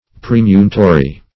Search Result for " premunitory" : The Collaborative International Dictionary of English v.0.48: Premunitory \Pre*mu"ni*to*ry\, a. Of or pertaining to a premunire; as, a premunitory process.